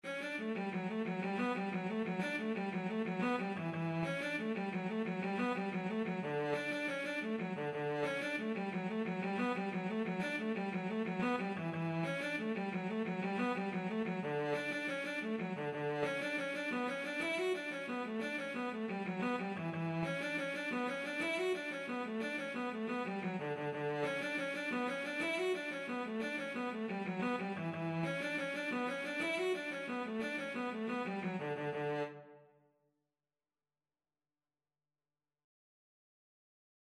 Free Sheet music for Cello
6/8 (View more 6/8 Music)
D major (Sounding Pitch) (View more D major Music for Cello )
Cello  (View more Intermediate Cello Music)
Traditional (View more Traditional Cello Music)